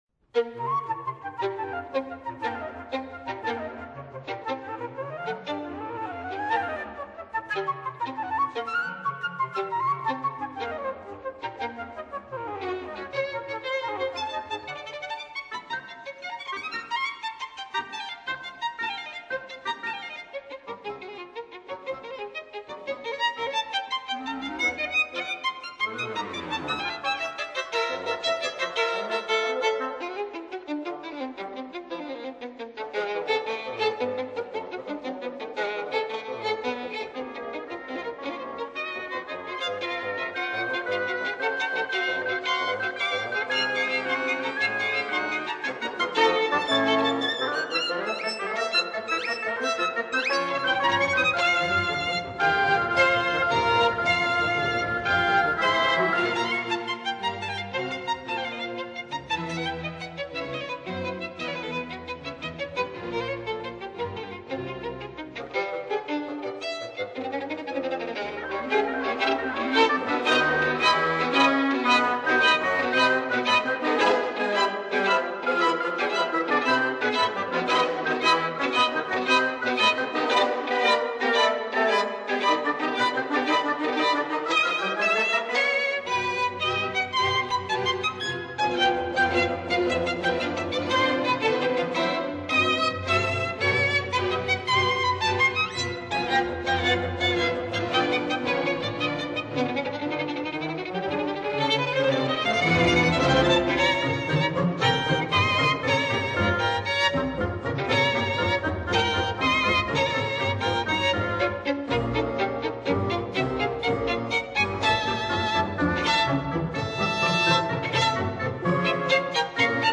分辑：CD18  小提琴协奏曲